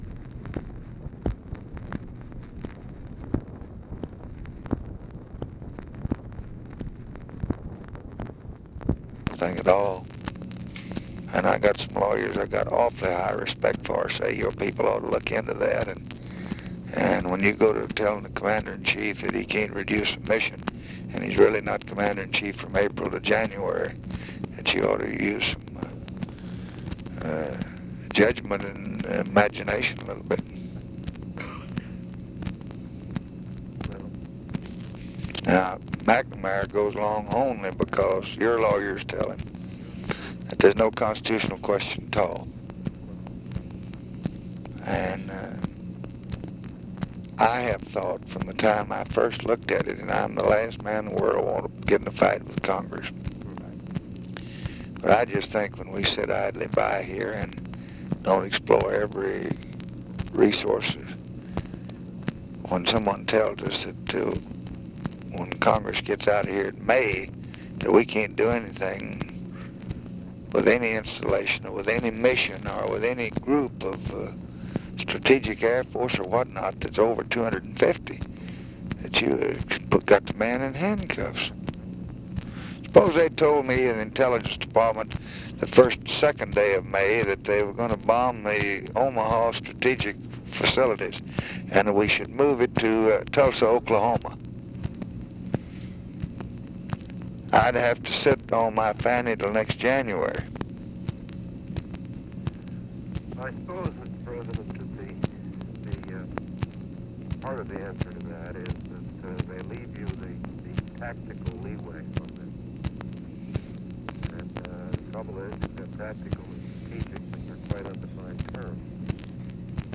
Conversation with NICHOLAS KATZENBACH, August 21, 1965